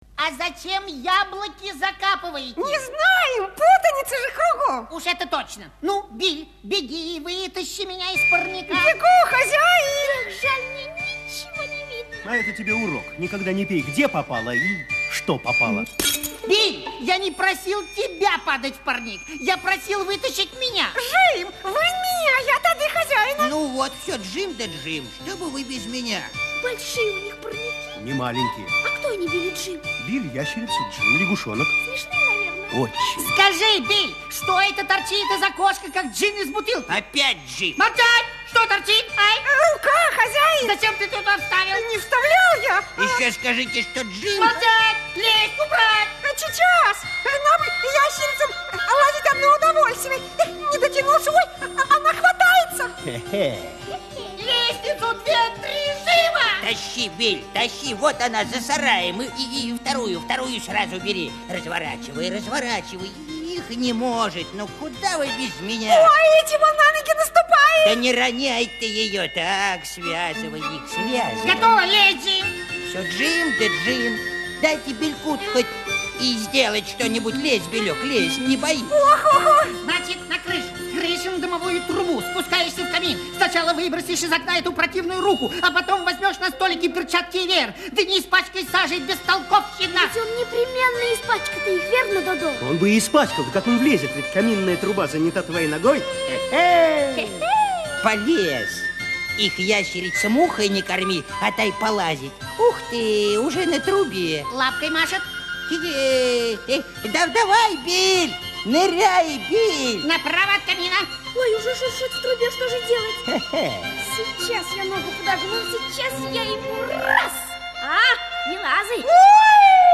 Музыкальная сказка
Инструментальный ансамбль
Соло на скрипке